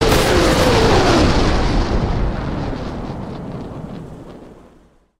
На этой странице собраны исторические записи звуков залпов «Катюши» — легендарной реактивной системы, ставшей символом победы в Великой Отечественной войне.
Звук запуска ракеты советской Катюши БМ-13